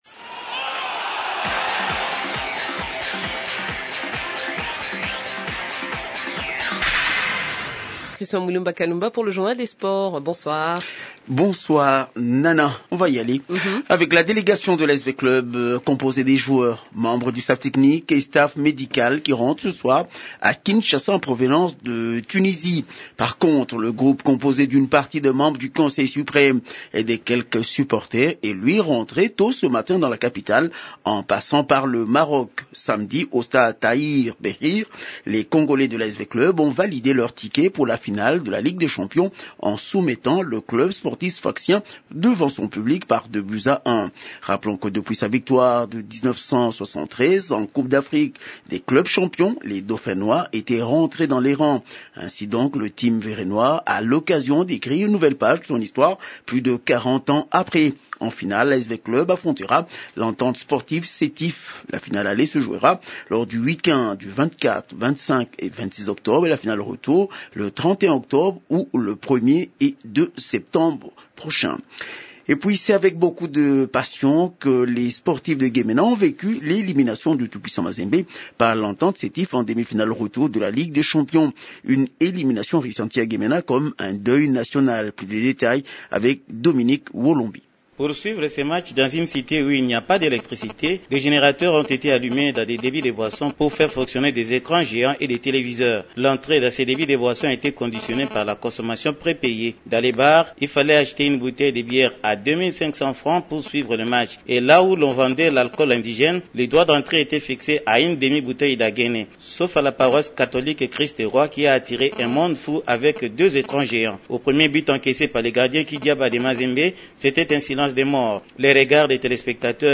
Journal des sports du 29 septembre 2014